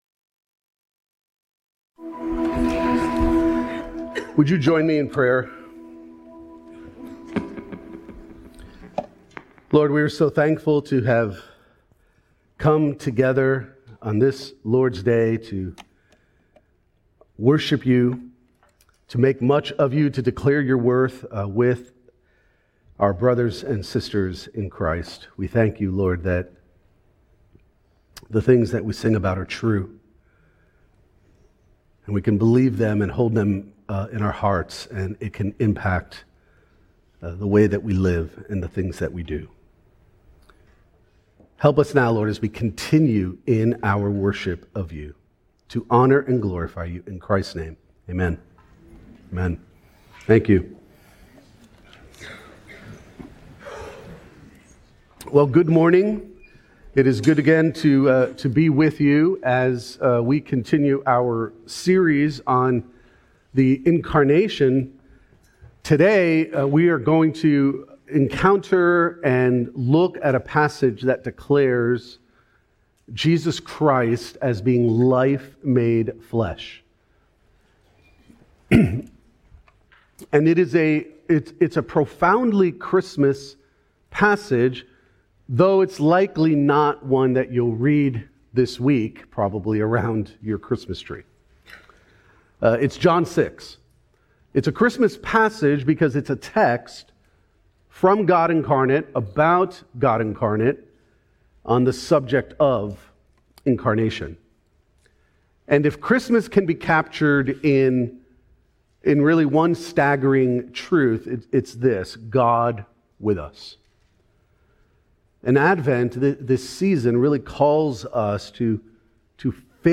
Sermons Christ Community Church: Daytona Beach, FL